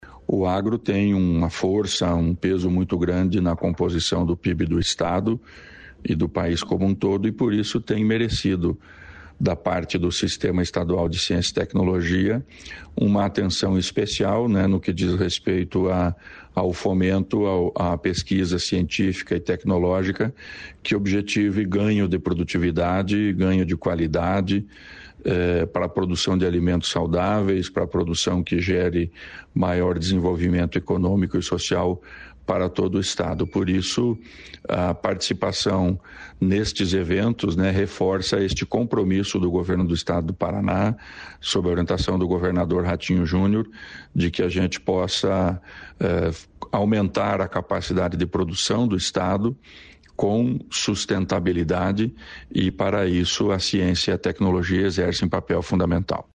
Sonora do secretário estadual da Ciência, Tecnologia e Ensino Superior do Paraná, Aldo Nelson Bona, sobre o Congresso do Agronegócio Global